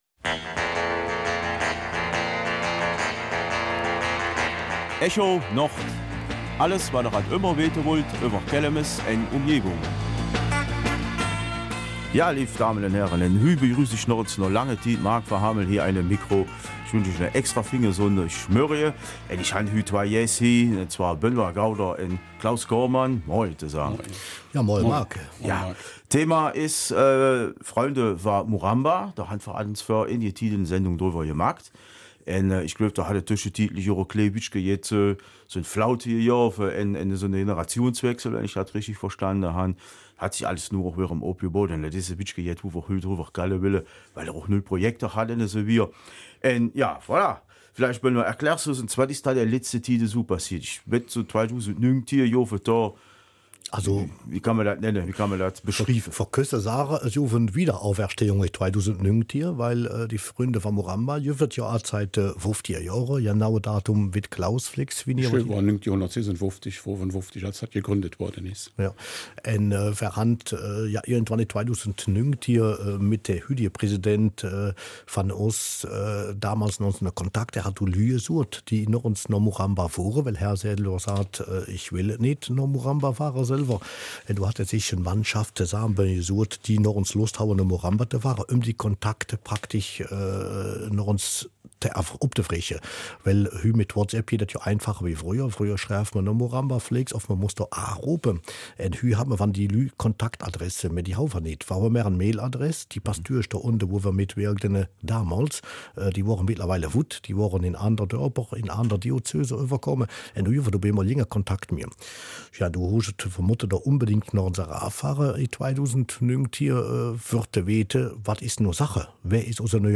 Kelmiser Mundart: Projekt Freunde von Muramba